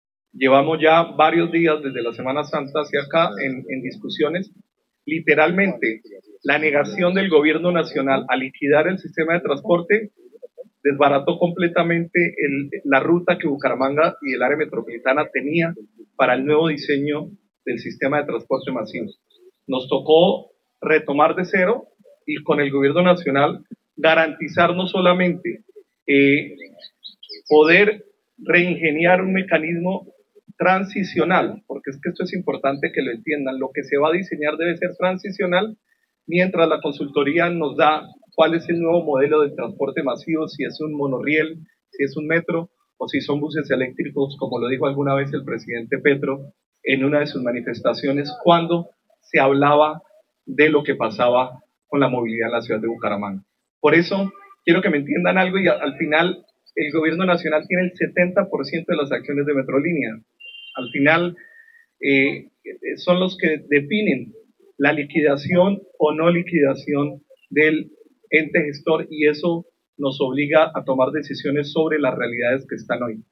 Alcalde de Bucaramanga, Jaime Andrés Beltrán